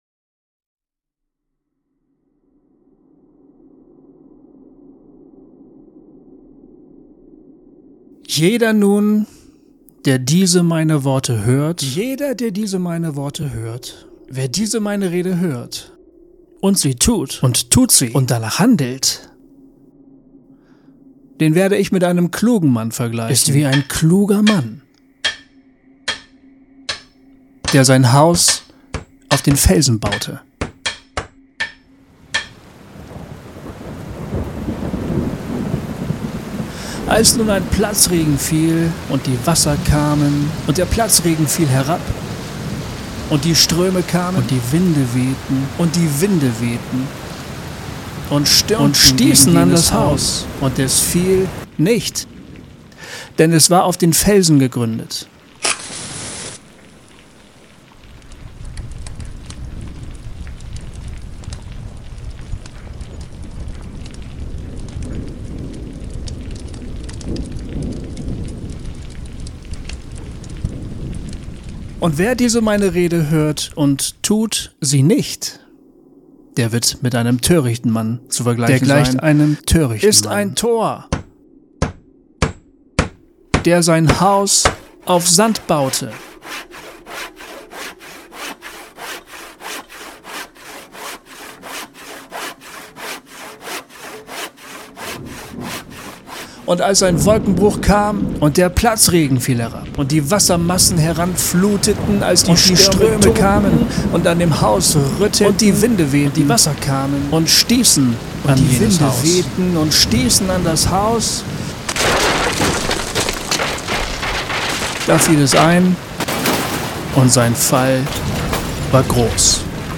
entfaltet ein vielschichtiges und intensives Hörerlebnis, in dem sich Bibeltext, atmosphärische Sounds und musikalische Fragmente miteinander verweben.